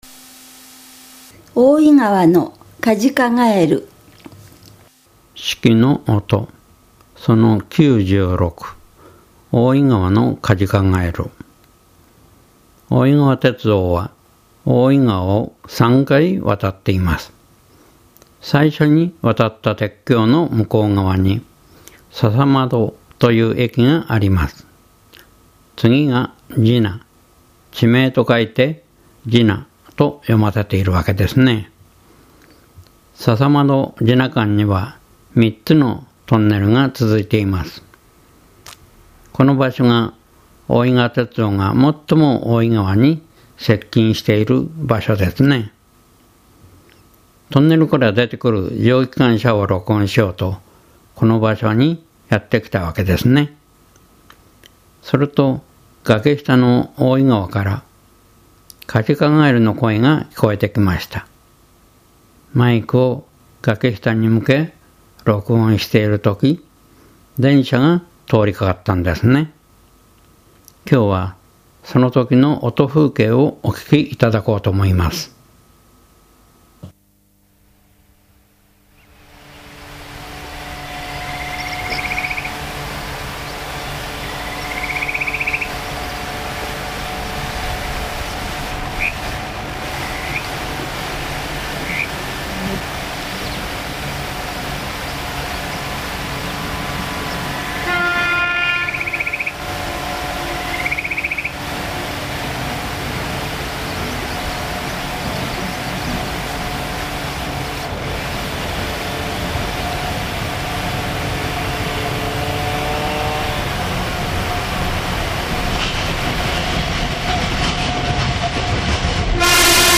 kazikagaeru096s.mp3